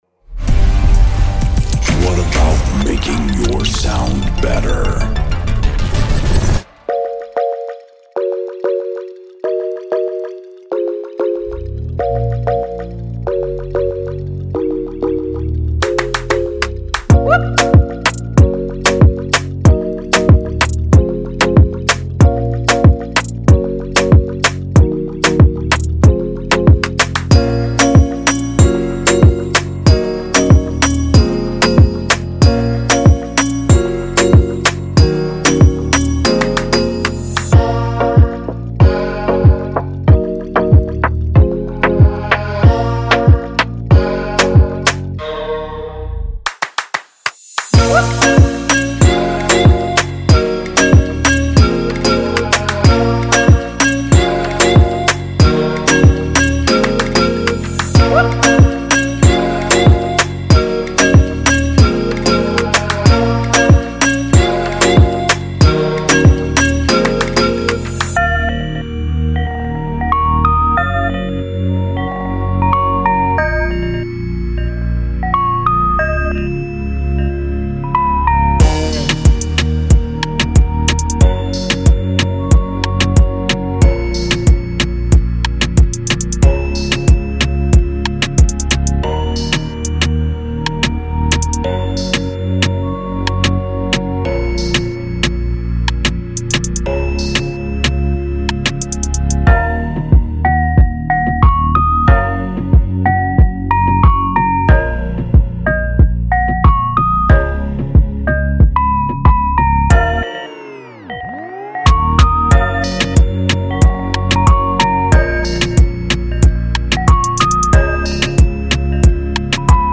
融合了嘻哈，拉丁和加勒比海风情，您将能够创建雷鬼，雷蒙巴顿，热带低音，嘻哈和热带房屋风格的单轨。
30 Melody Loops (+ MIDI)
30 Drum Shots (Kicks, Claps, Snares, Percussion, Cymbals)